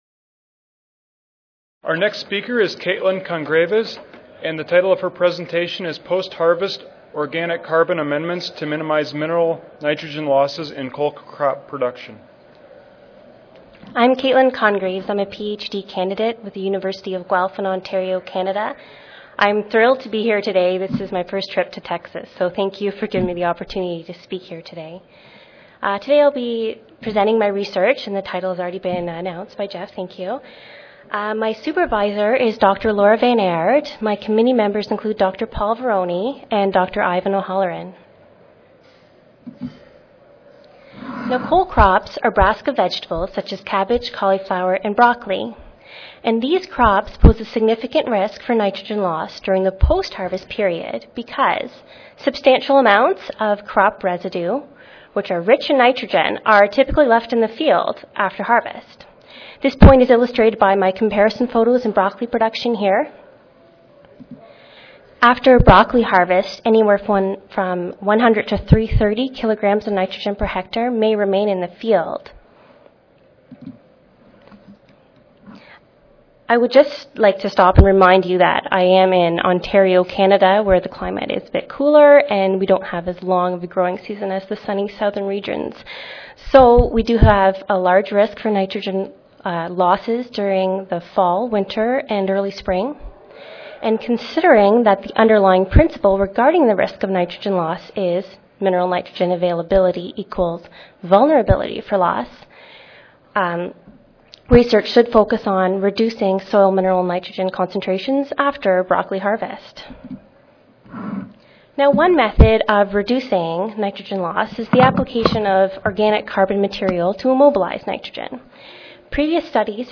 S08 Nutrient Management & Soil & Plant Analysis Session: S4/S8 Graduate Student Oral Competition-Nitrogen Management Strategies to Maximize Crop Productivity and Minimize Loss (ASA, CSSA and SSSA Annual Meetings (San Antonio, TX - Oct. 16-19, 2011))
Recorded Presentation